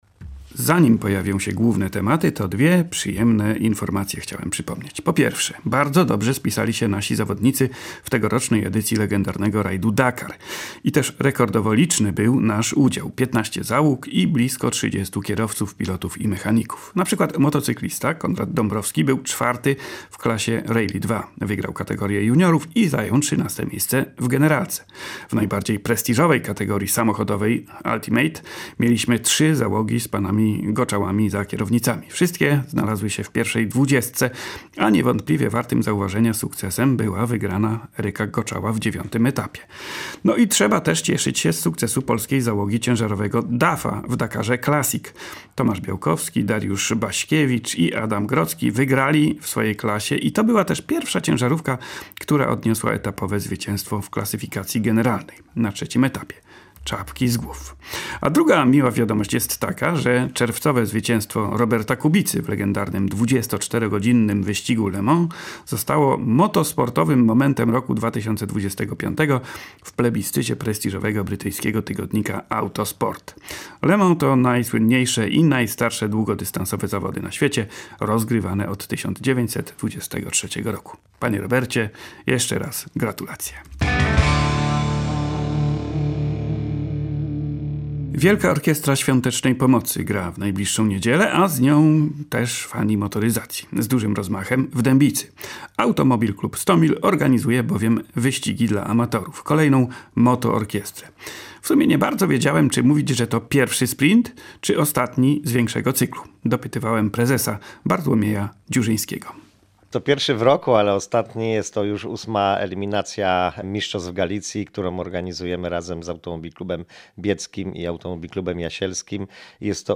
W audycji rozmowa o motorsportowych akcentach Wielkiej Orkiestry Świątecznej Pomocy – bo tradycyjnie finał WOŚP to także okazja do bezpiecznego ścigania się samochodami na zamkniętych obiektach.